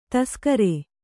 ♪ taskare